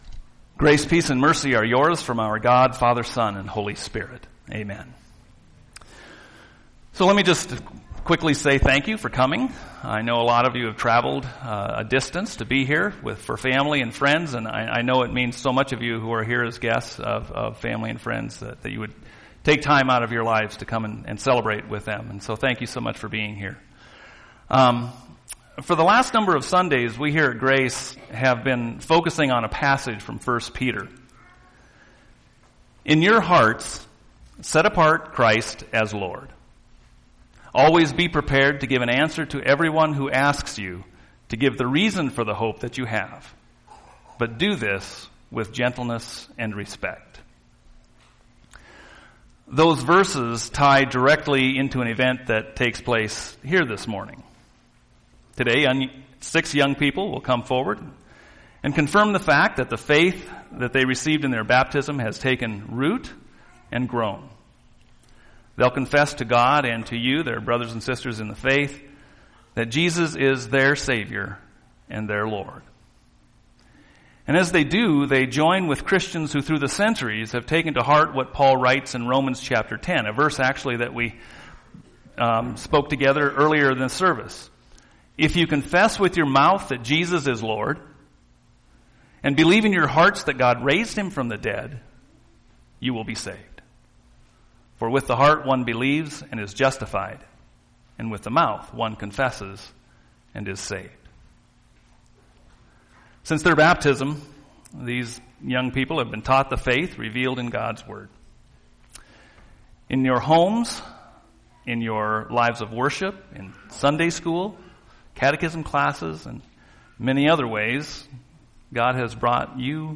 Sunday Service Sermons